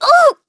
Xerah-Vox_Damage_02.wav